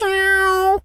cat_meow_03.wav